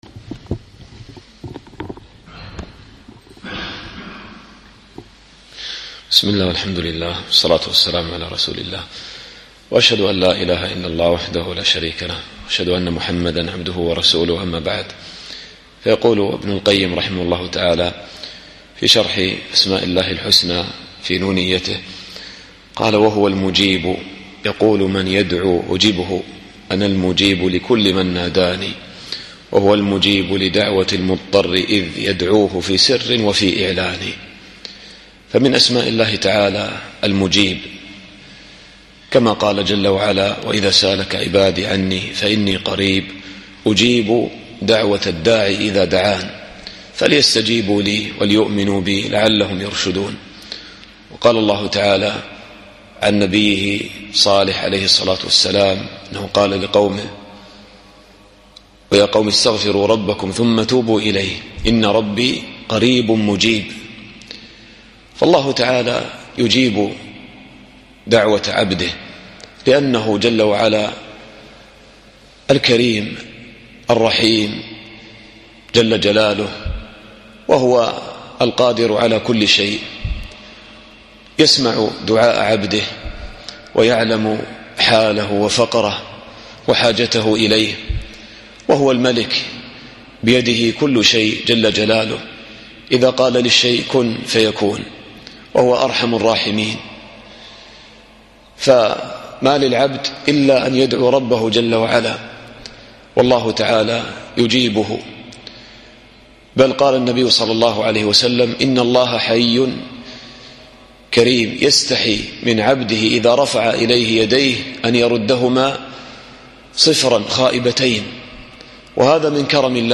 الدرس السابع والعشرون